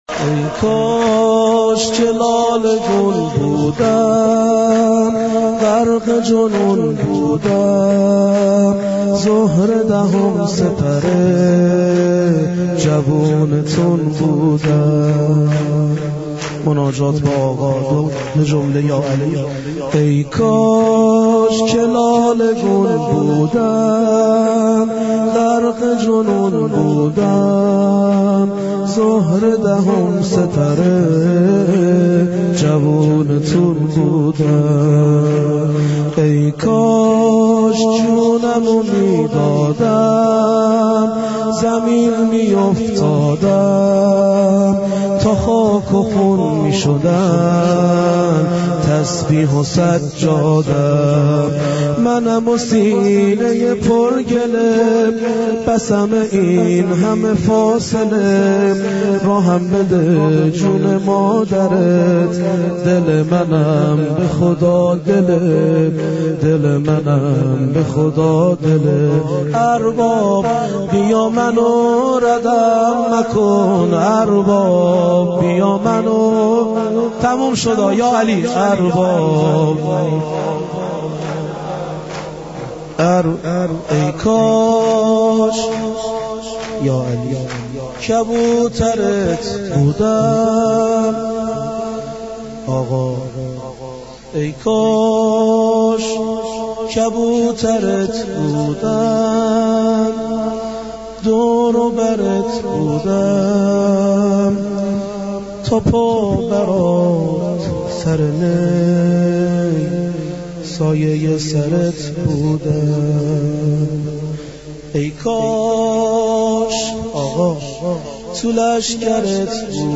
مراسم مناجات با خدا در حسینیه ام البنین(س) اهواز
مداحی و سینه زنی